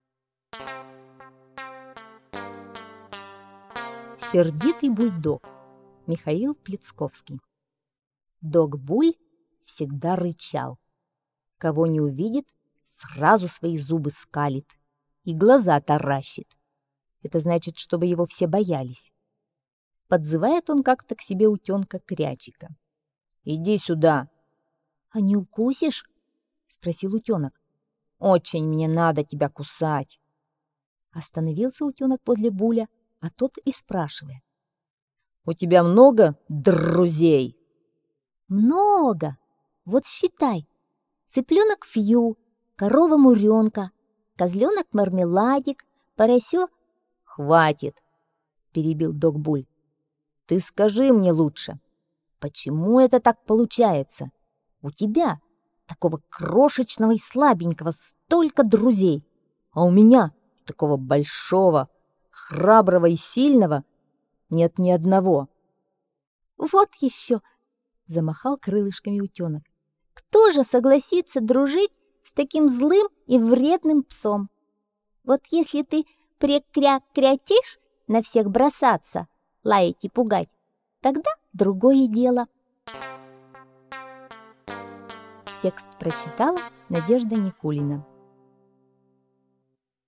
Сердитый Бульдог - аудиосказка Пляцковского М.С. История о том, как утенок Крячик объяснял сердитому бульдогу Булю, почему у него нет друзей.